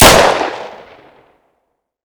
sr2m_shoot.ogg